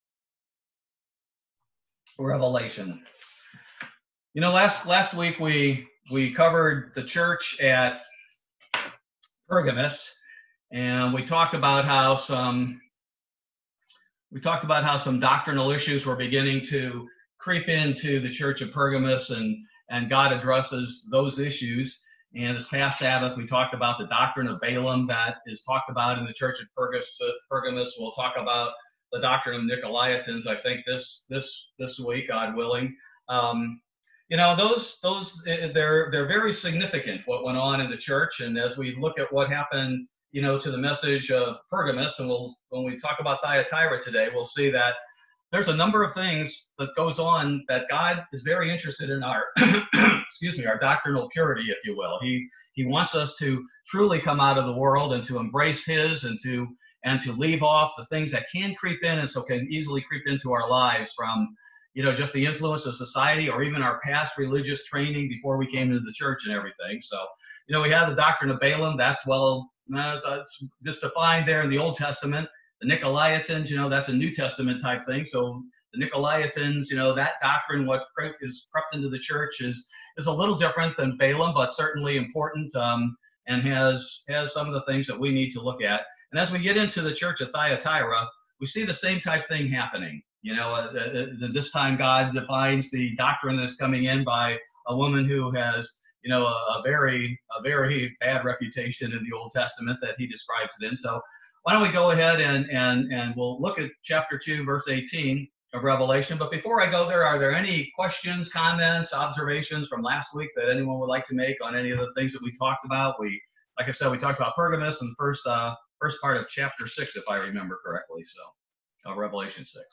Bible Study - July 22, 2020